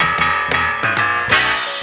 keys_19.wav